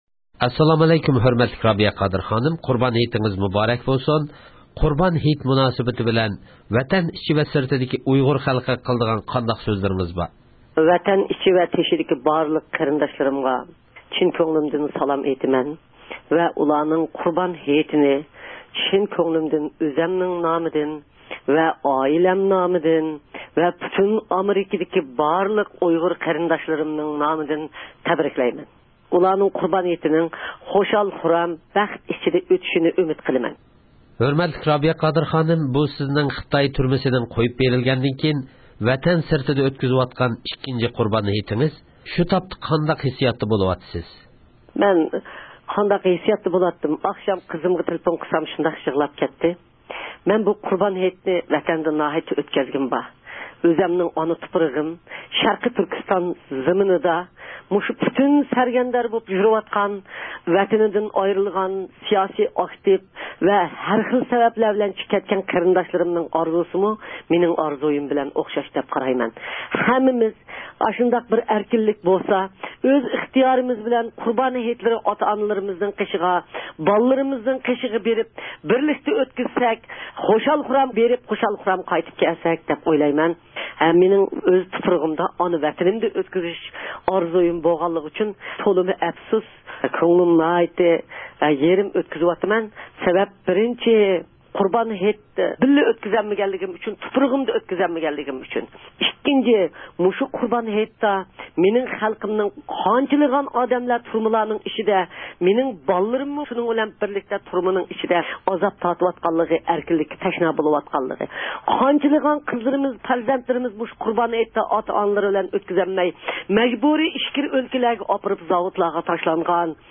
ئۇيغۇرلارنىڭ مەنىۋى ئانىسى، دۇنيا ئۇيغۇر قۇرۇلتىيىنىڭ رەئىسى رابىيە قادىر خانىم قۇربان ھېيت ۋە يېڭى يىل مۇناسىۋىتى بىلەن زىيارىتىمىزنى قوبۇل قىلىپ، ۋەتەنداشلارنىڭ قۇربان ھەيتى ۋە يېڭى يىلىنى تەبرىكلىدى شۇنداقلا تىلەكلىرىنى ئىپادىلىدى.